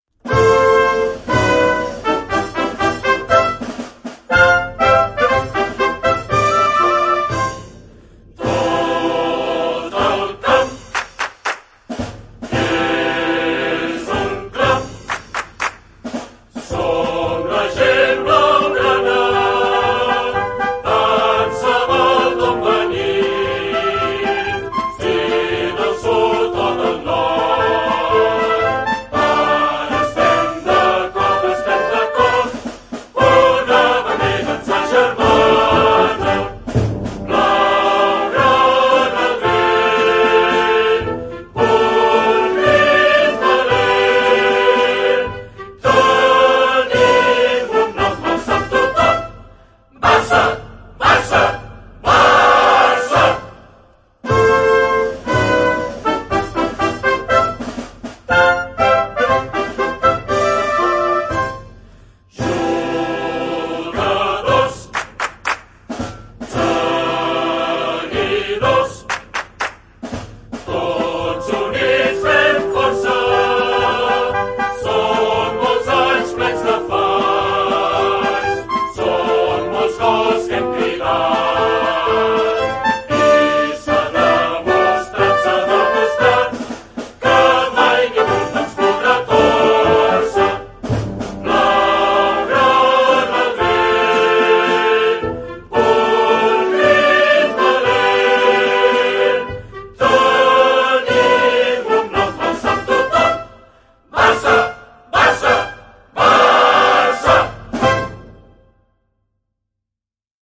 Official Anthem